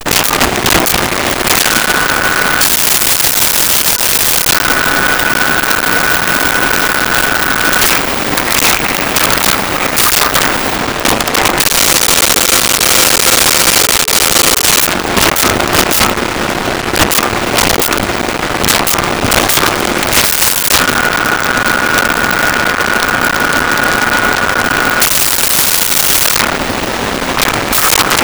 Phone Speaker Dial Ring
Phone Speaker Dial Ring.wav